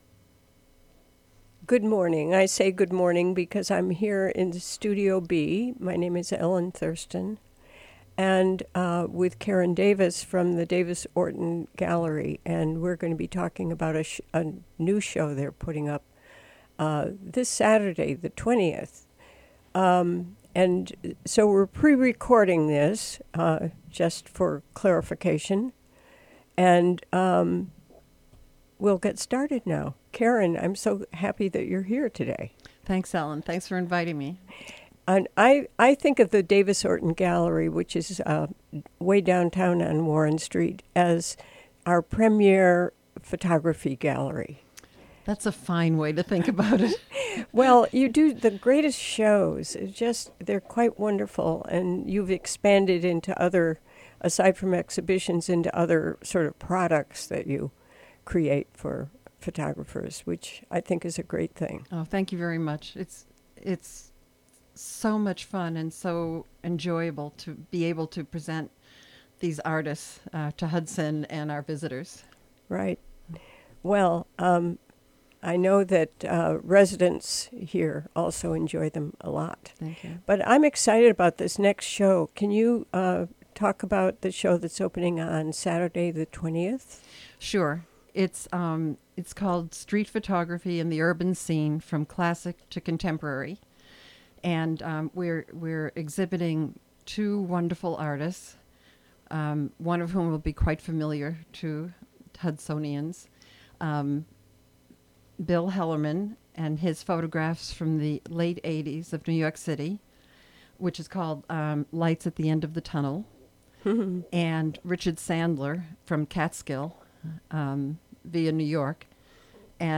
Prerecorded in the WGXC Hudson Studio on Tuesday, May 16, 2017.